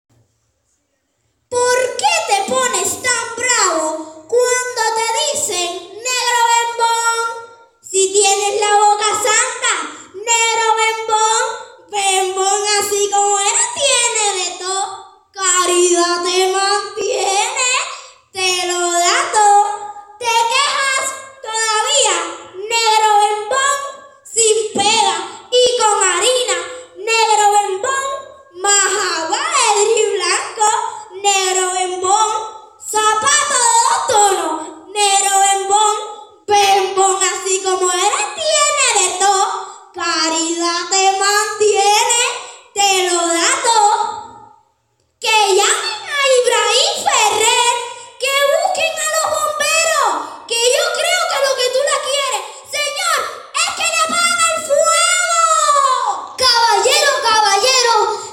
Con el merecidísimo reconocimiento a esa gran fuerza de la cultura que son los instructores de arte finalizó en la noche de este domingo la Jornada de la Cultura Pinera en el cine teatro Caribe.
Momento especial de la noche lo constituyó la declamación de Negro bembón, uno de los poemas de Motivos del son del poeta nacional Nicolás Guillén.